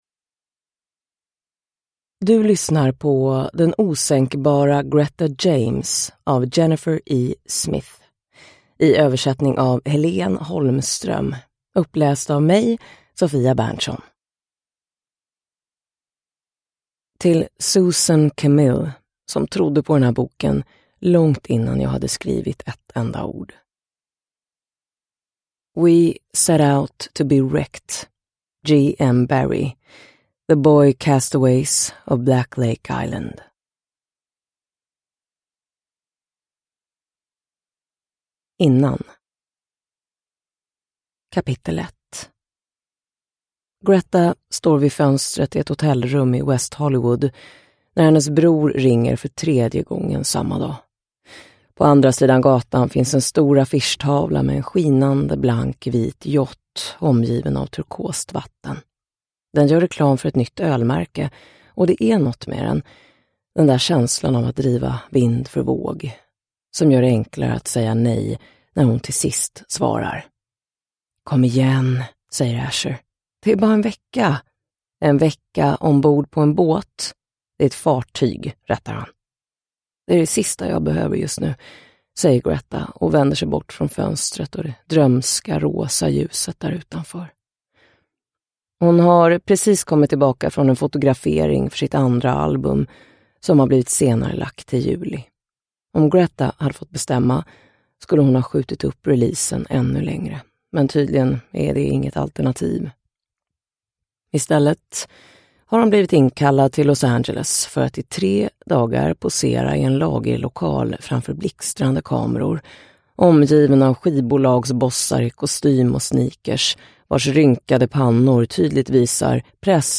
Den osänkbara Greta James – Ljudbok – Laddas ner